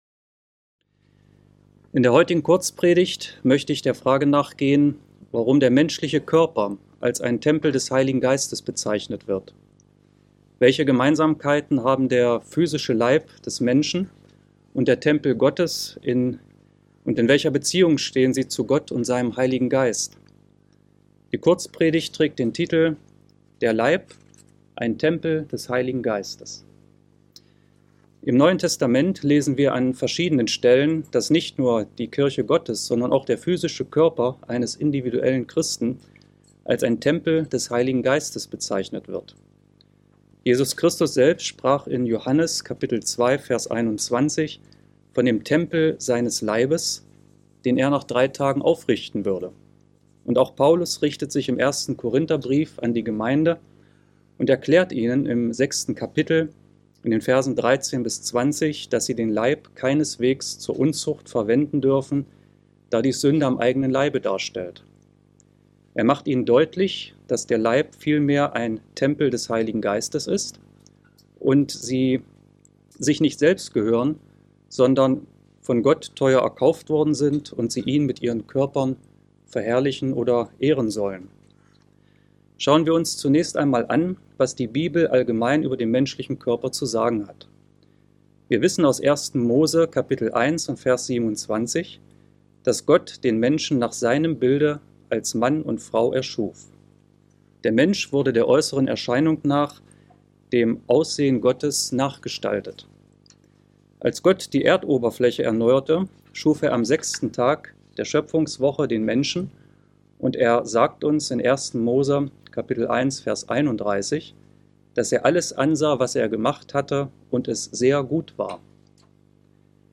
Die Kurzpredigt geht der Frage nach, welche Gemeinsamkeiten der physische Leib des Menschen und der Tempel Gottes haben und in welcher Beziehung sie zu Gott und seinem Heiligen Geist stehen? Dabei wird auf den menschlichen Körper und den Salomonischen Tempel etwas näher eingegangen.